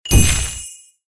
ui_interface_15.wav